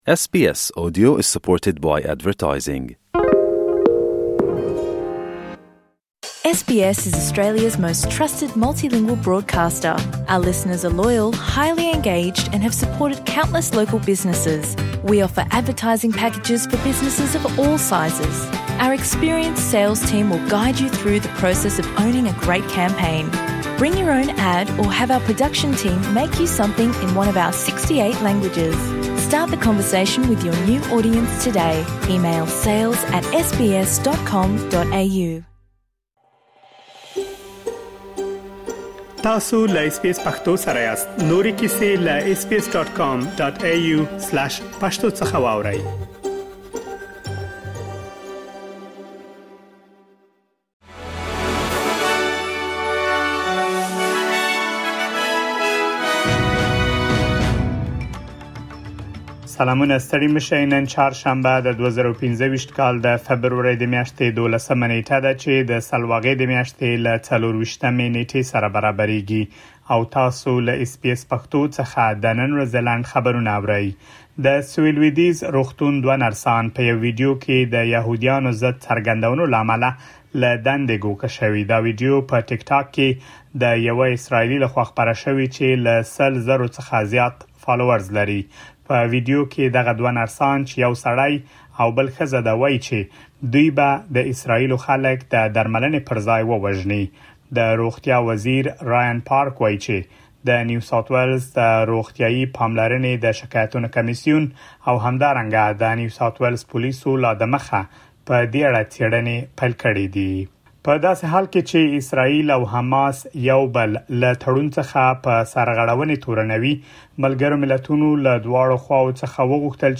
د اس بي اس پښتو د نن ورځې لنډ خبرونه | ۱۲ فبروري ۲۰۲۵